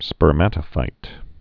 (spər-mătə-fīt, spûrmə-tə-)